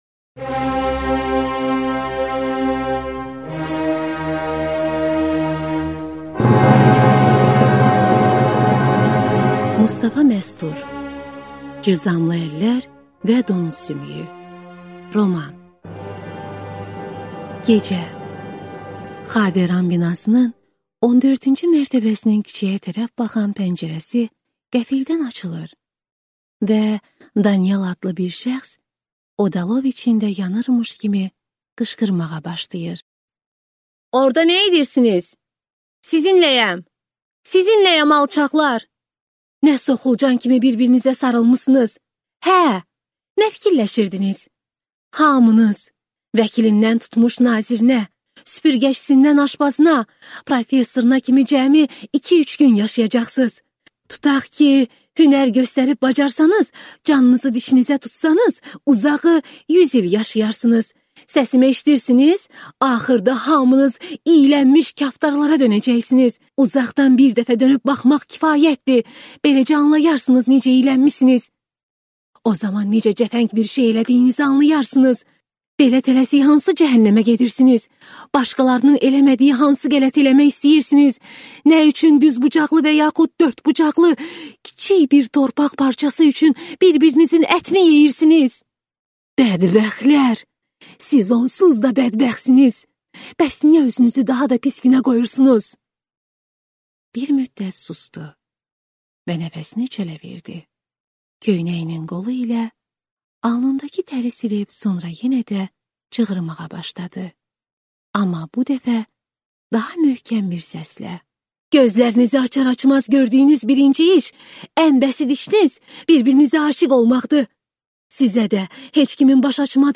Аудиокнига Cüzamlı əllər və donuz sümüyü | Библиотека аудиокниг